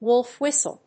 アクセントwólf whìstle